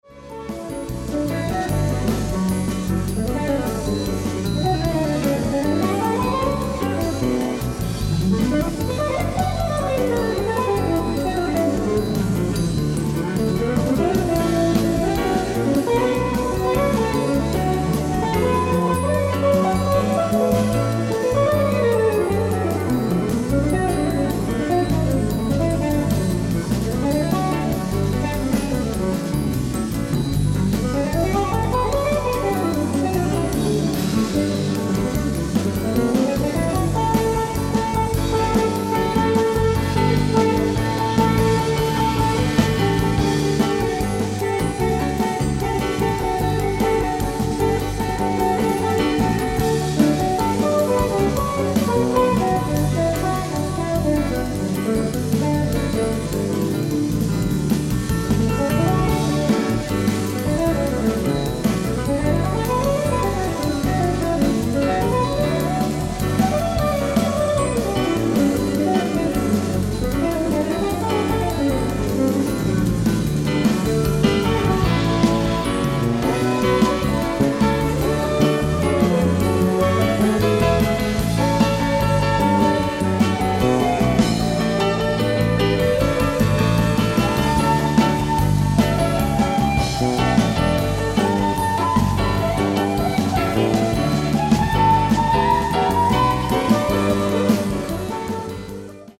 ライブ・アット・ノーマン、オークランド、USA 07/22/1982
※試聴用に実際より音質を落としています。